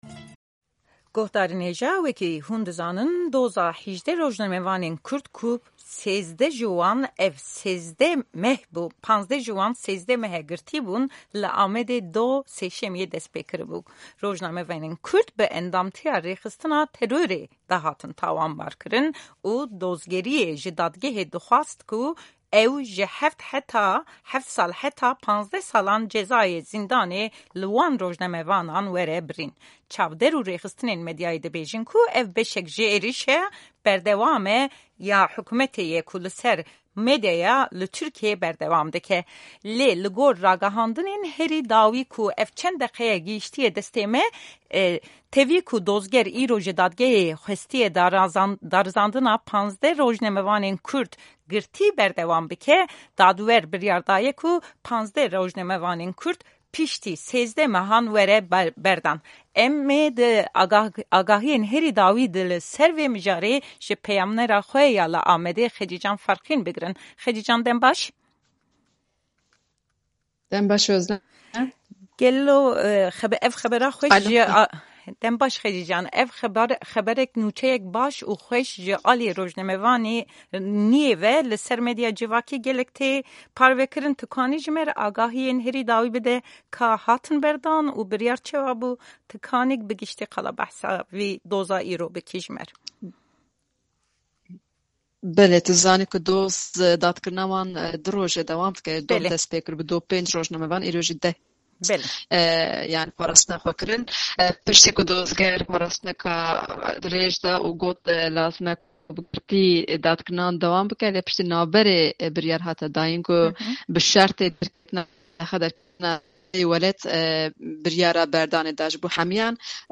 زانیاری زیاتر لەم وتووێژەی